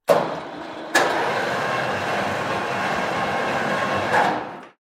Звуки автосервиса
Шум электроподъемника в мастерской